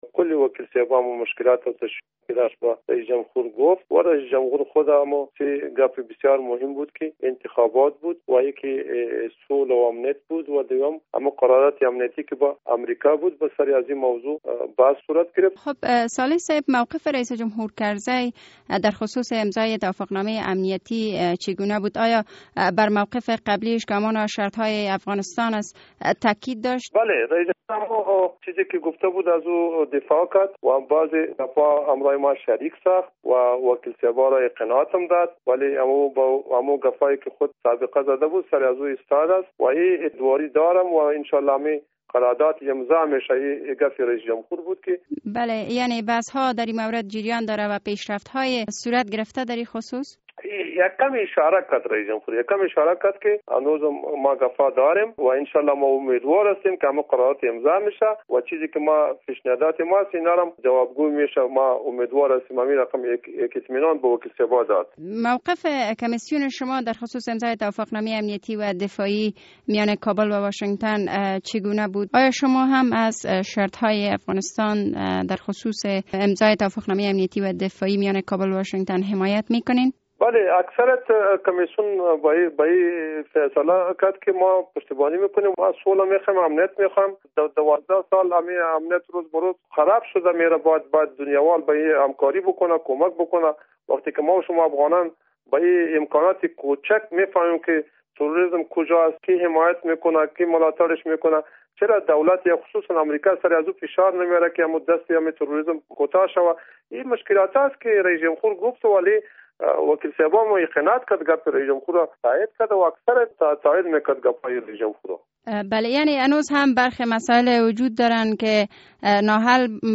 مصاحبه درمورد ملاقات اعضای کمیسیون روابط بین المللی و امنیت داخلی ولسی جرگه با حامد کرزی